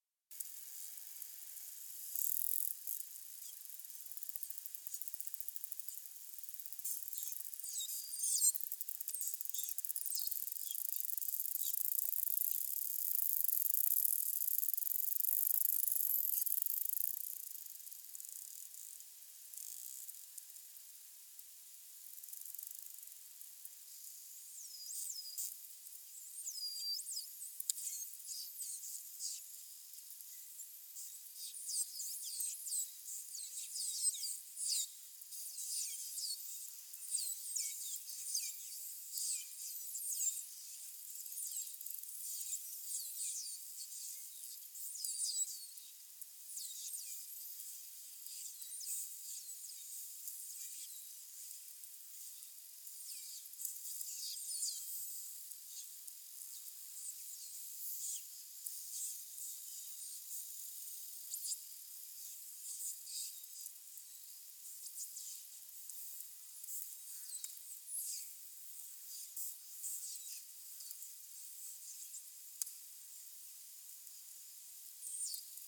In particolare, è stato acquistato un idrofono (un microfono progettato per essere utilizzato sott’acqua) che si è aggiunto alla strumentazione in dotazione al progetto. Si tratta di un sistema idrofonico stazionario – cioè creato per essere immerso in condizioni statiche – che riesce a captare suoni e ultrasuoni a frequenze fino a 120 kHz, permettendo l’acquisizione dei suoni emessi dagli animali.
I delfini infatti utilizzano le vibrazioni sonore sia come via di comunicazione (suoni a bassa frequenza, principalmente ‘fischi’) che come strumento di ecolocalizzazione (suoni ad alta frequenza denominati ‘click’) per l’individuazione delle prede e la localizzazione di possibili ostacoli presenti nella colonna d’acqua (in allegato una registrazione acustica di tursiopi durante un’uscita del progetto; sono udibili sia i fischi che i click).
Rec_tursiopi4.mp3